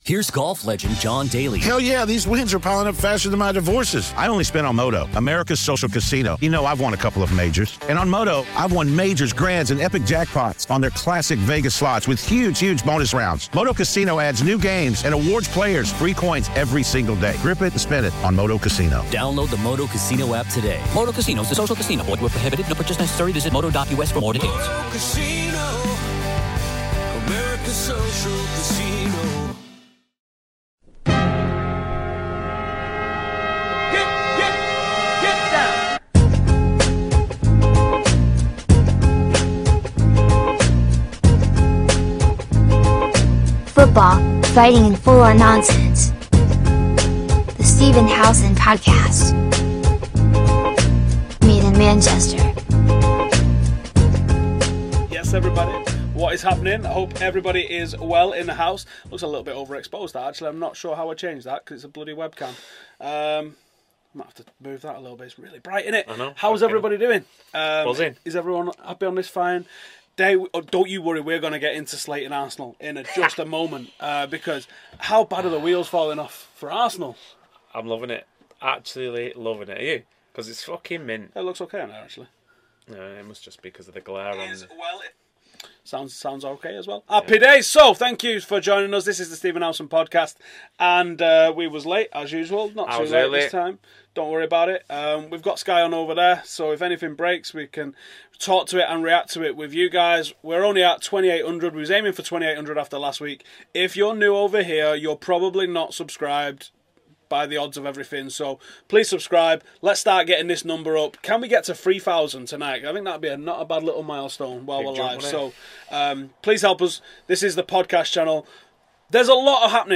Arsenal FC Meltdown! | DEADLINE DAY LIVE